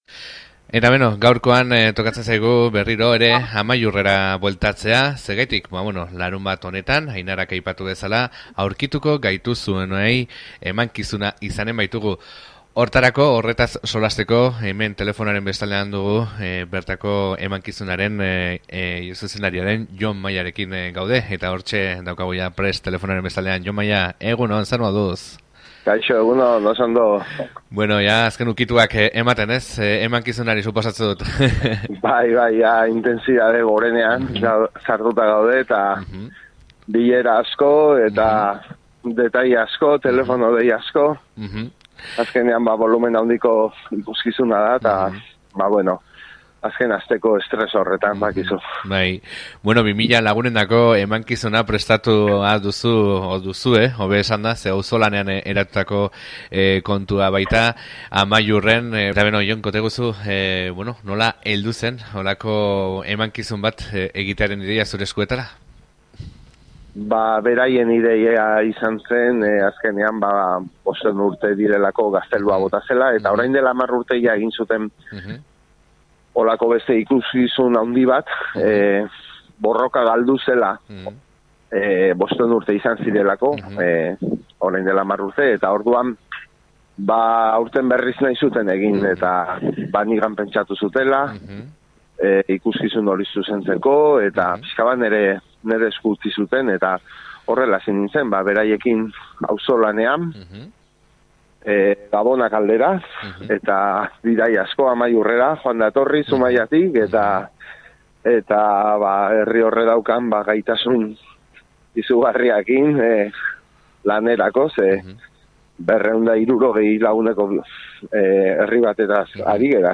Iraganera begira egon ordez, etorkizunean izanen diren arkeologoei zer nolako herria garen erakutsi nahi duen emankizuna da eta bere zuzendaria den Jon Maiarekin izan gara gaurkoan honen inguruan solasean.